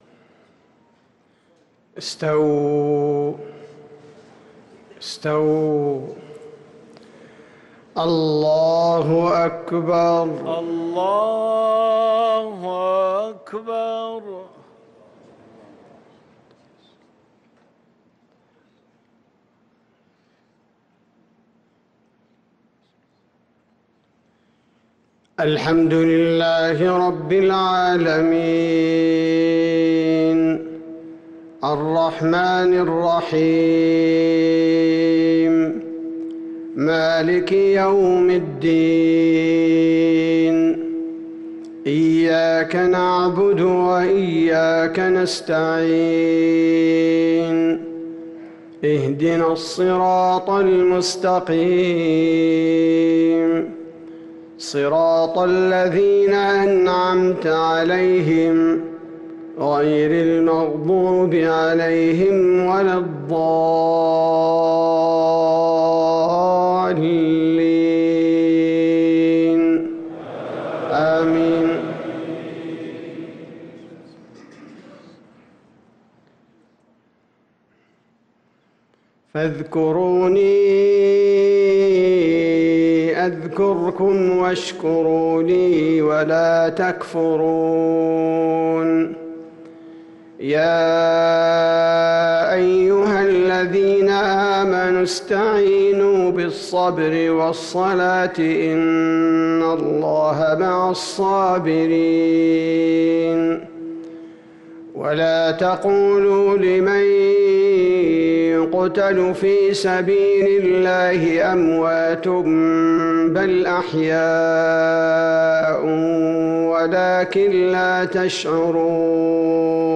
صلاة المغرب للقارئ عبدالباري الثبيتي 11 ذو الحجة 1444 هـ
تِلَاوَات الْحَرَمَيْن .